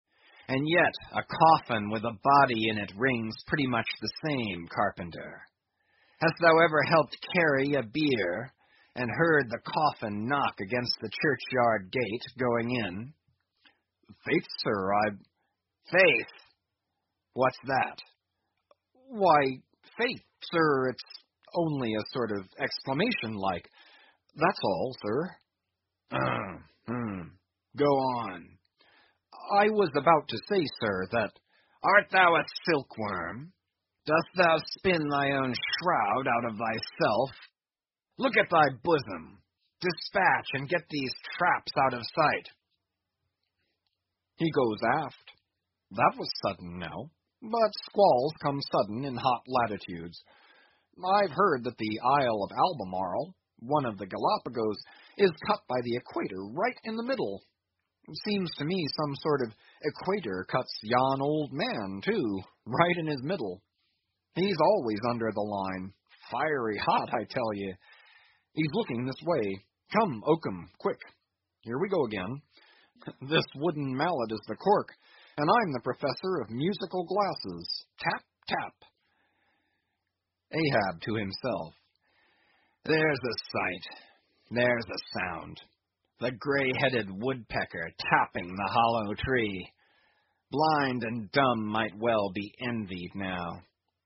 英语听书《白鲸记》第976期 听力文件下载—在线英语听力室